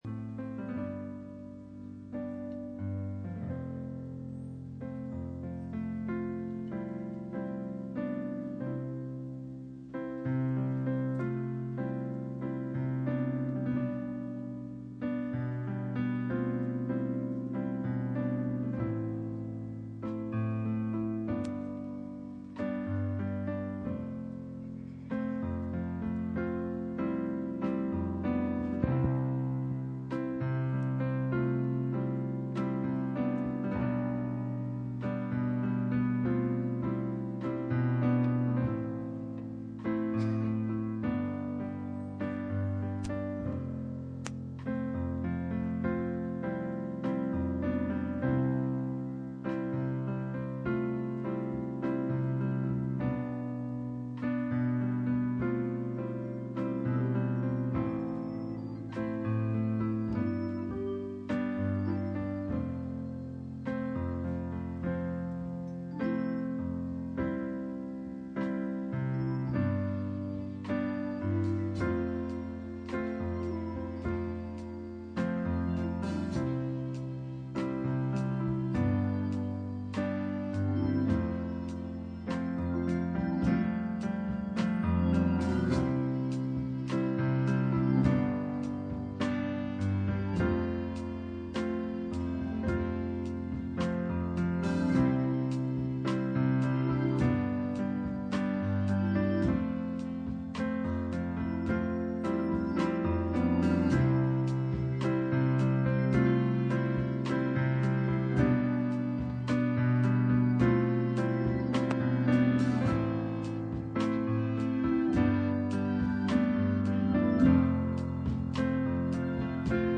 Isaiah 59:17-19 Service Type: Sunday Morning %todo_render% « Church Ages